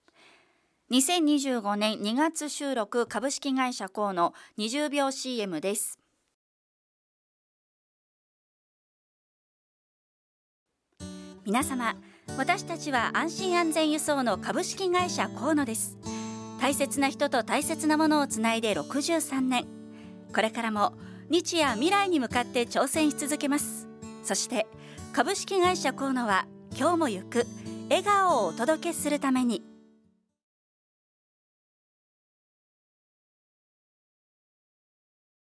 ラジオCM音声はこちら